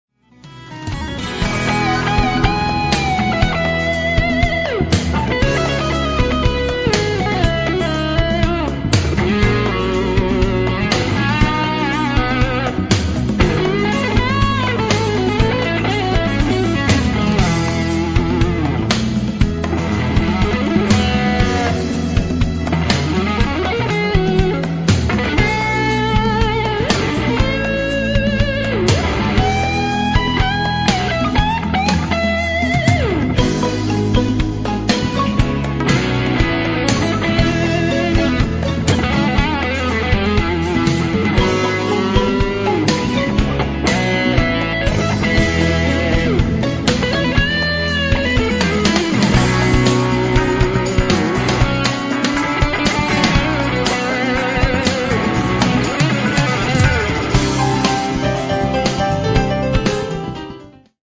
akustična+električna gitara, E-dur